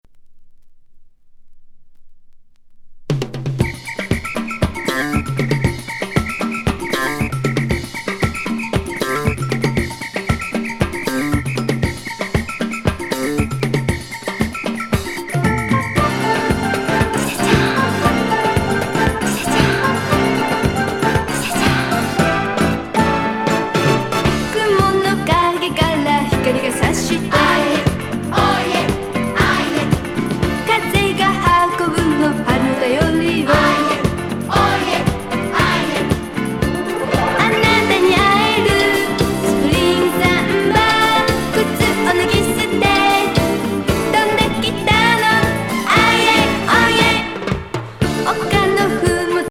ホイッスル&ストリングスのサンバ歌謡＋アーイエーコーラス!